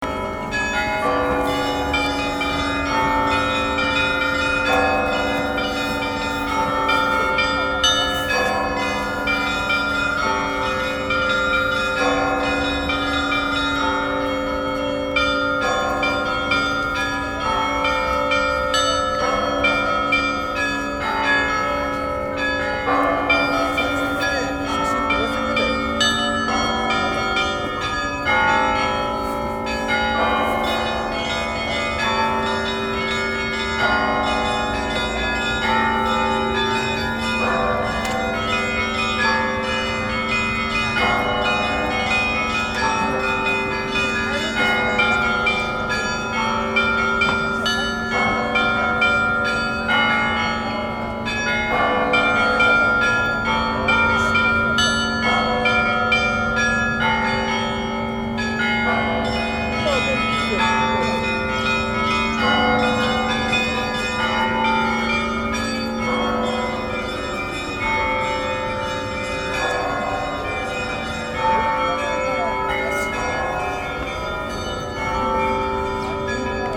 教会の中は写真撮影をできないと聞いていましたが、結婚式のセレモニーが行われており、撮影が許されました。
ロシア正教会のチャイム　（生録音）
amalty-charch.MP3